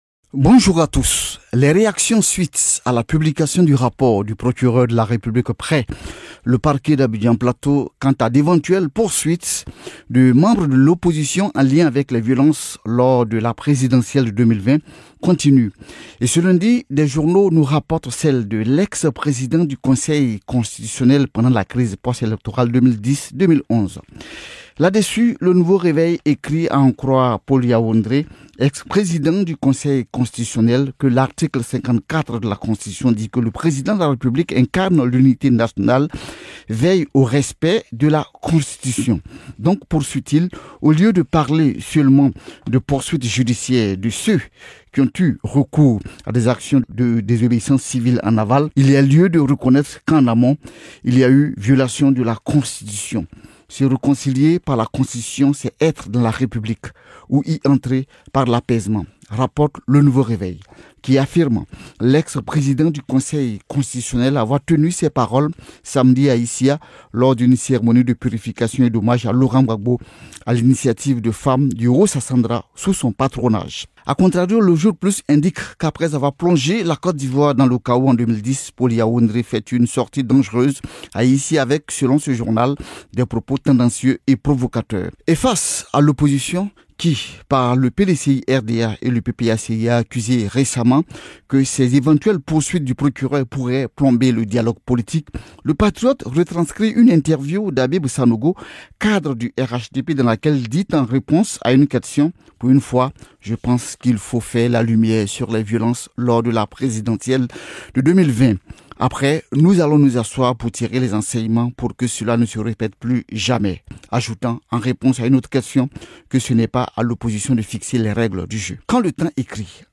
Revue de la presse ivoirienne datant du 10 janvier 2022. Explication des articles les plus importants concernant l'actualité ivoirienne.